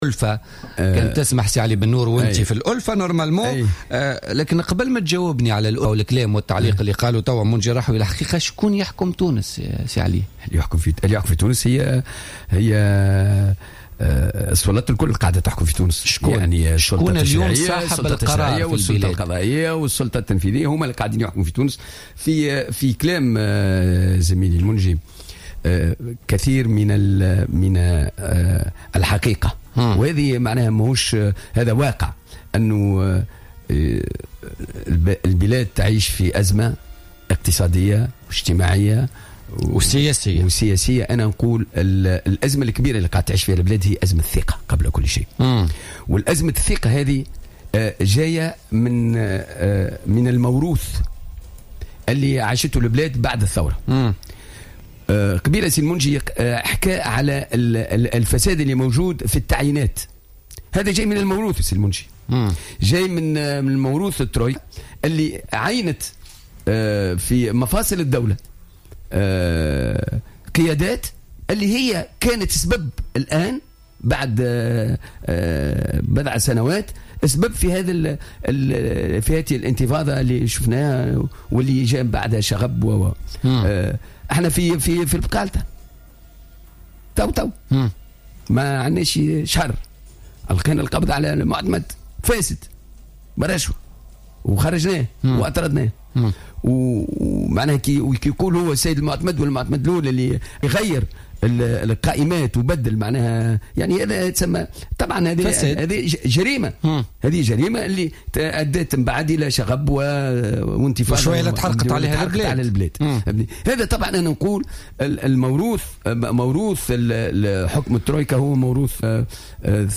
أكد النائب عن افاق تونس بمجلس نواب الشعب علي بنور ضيف بوليتيكا اليوم الخميس 28 جانفي 2016 أن موروث حكم الترويكا موروث ثقيل.